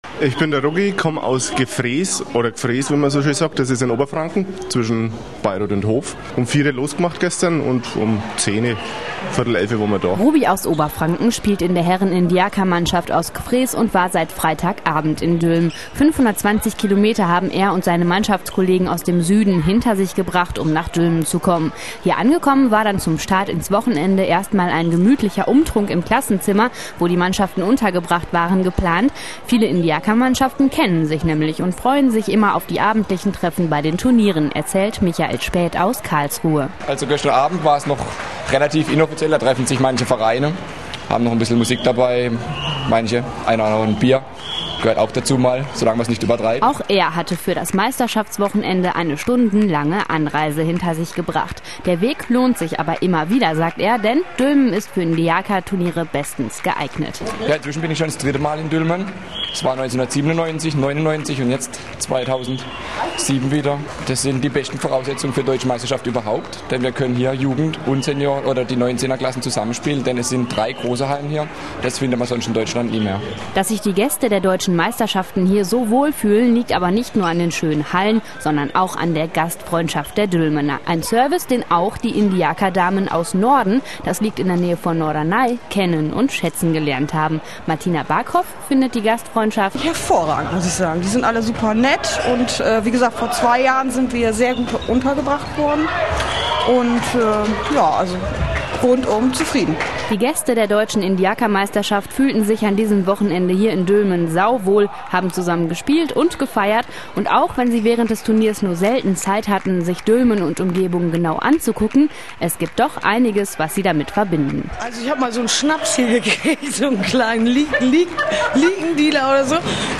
Hier gibt es alle TV-Berichte und Radioreportagen zu sehen bzw. zu h�ren, die rund um die Sportart Indiaca ab dem Jahr 2005 ausgestrahlt wurden.